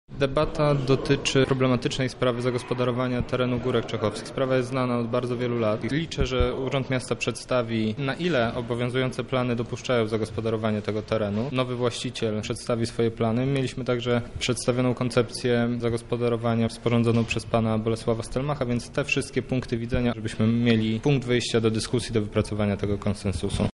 O celach debaty mówi Maciej Kowalczyk, Przewodniczący Zarządu Dzielnicy Czechów Południowy.